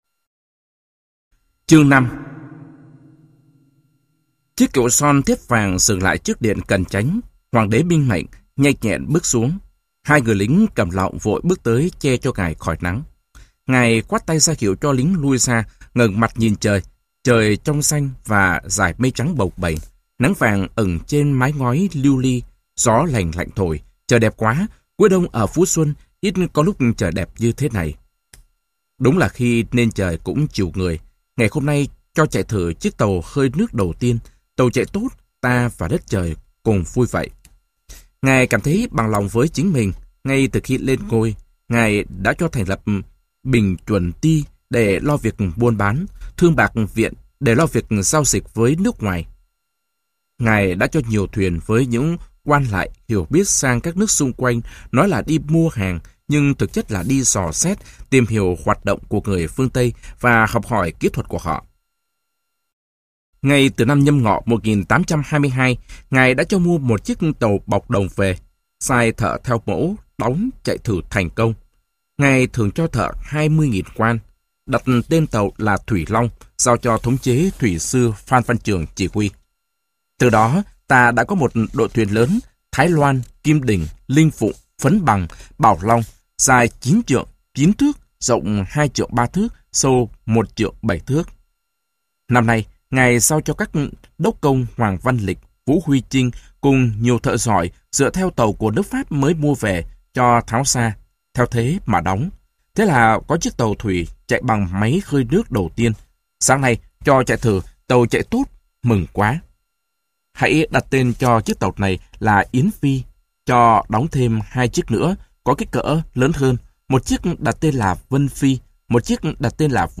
Sách nói Thông Reo Ngàn Hống - Nguyễn Thế Quang - Sách Nói Online Hay